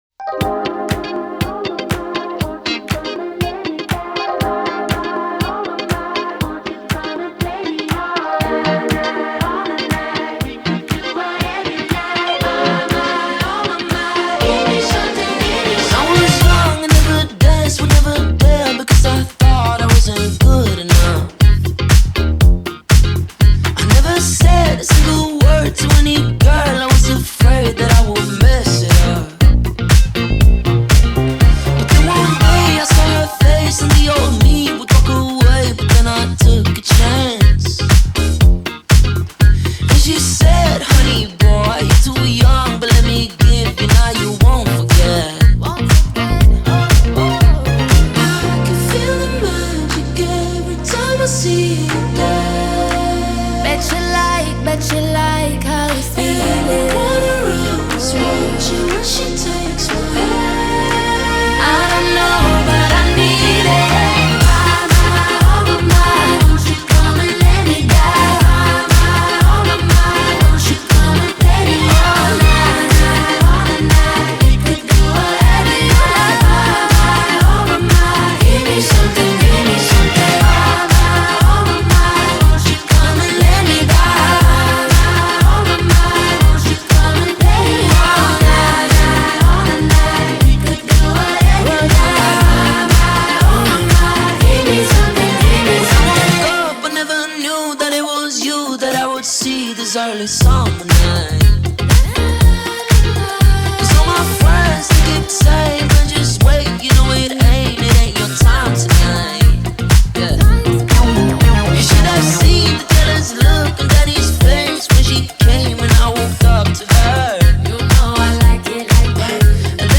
Это скорее мягкий вариант Lady Gaga